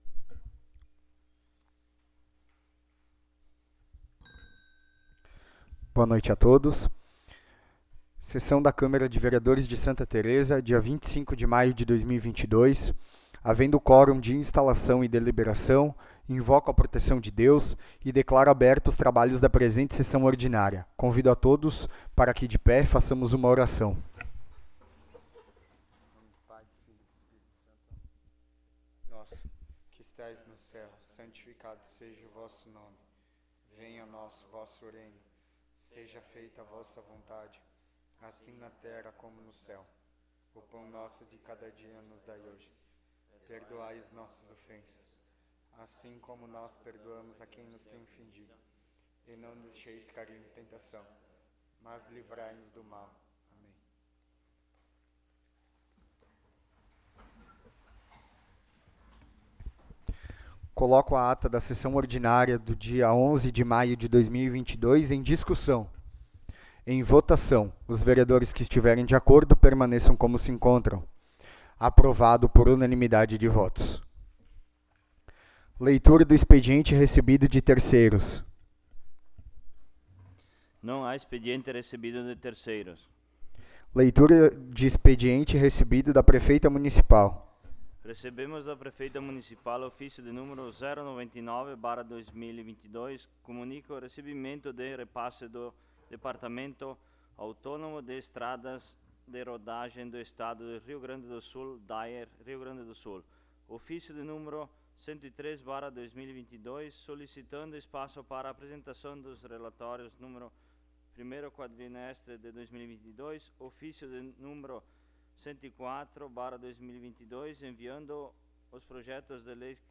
8ª Sessão Ordinária de 2022
Áudio da Sessão